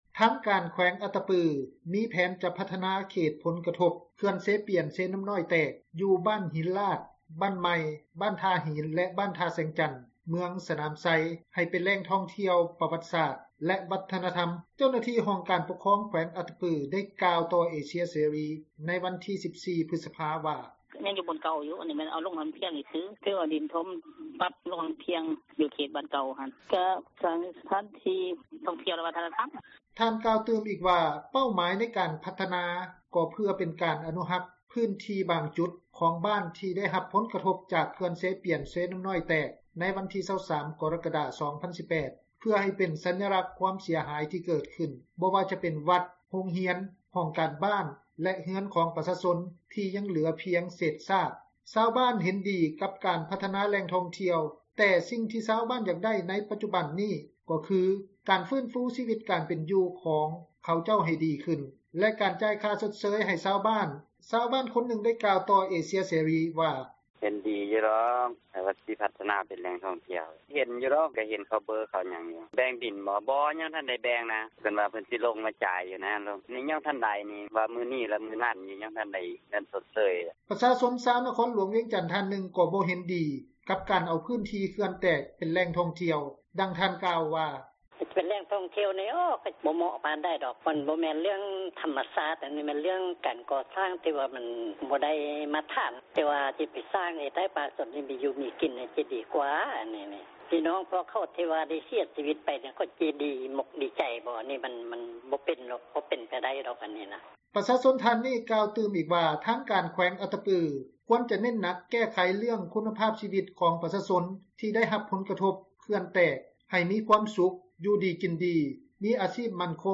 ຊາວບ້ານ ຄົນນຶ່ງໄດ້ກ່າວຕໍ່ ເອເຊັຽເສຣີ ວ່າ:
ປະຊາຊົນຊາວນະຄອນຫລວງວຽງຈັນ ທ່ານນຶ່ງ ບໍ່ເຫັນດີກັບການເອົາພື້ນທີ່ເຂື່ອນແຕກ ເປັນແຫຼ່ງທ່ອງທ່ຽວ, ດັ່ງທ່ານກ່າວວ່າ: